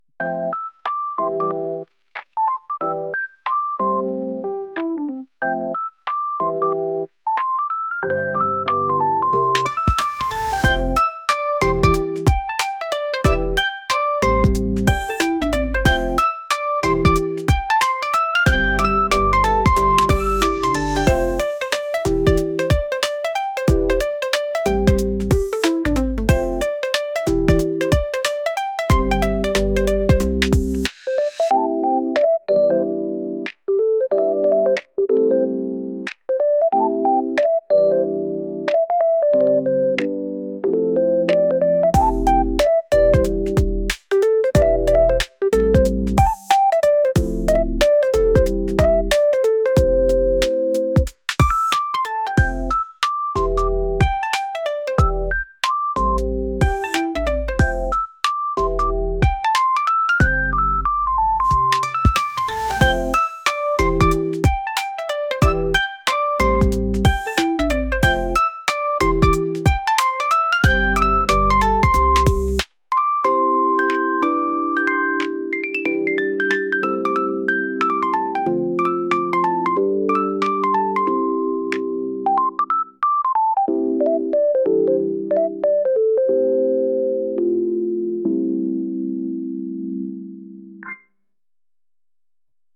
夜更かしのまったりしたlo-fi曲です。